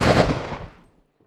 AssaultCanon_3p_tail.wav